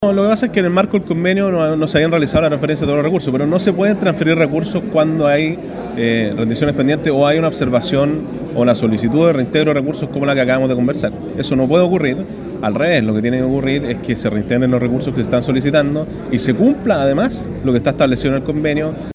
Además, a raíz de este mismo antecedente es que el Gobierno Regional no ha transferido 65 millones de pesos que la fundación asegura, se le adeuda desde el año pasado, como lo señaló el gobernador Giacaman.